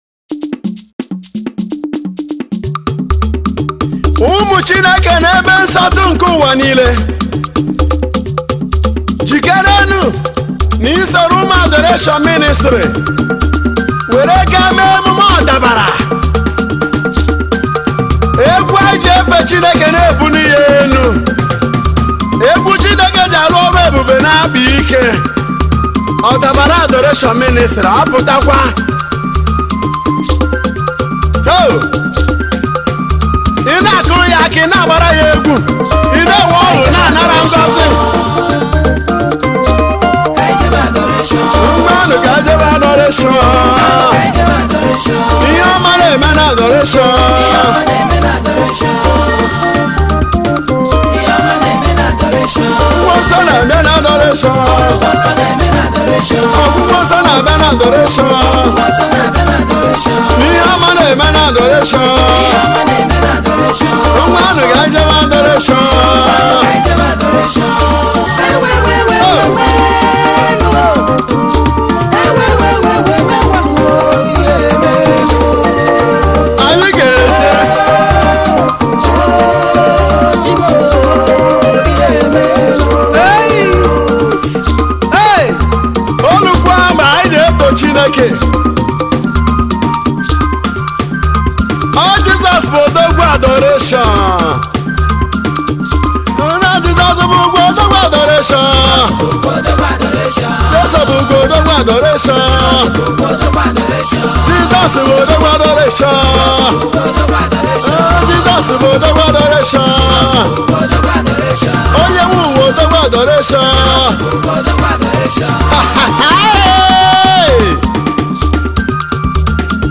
February 8, 2025 Publisher 01 Gospel 0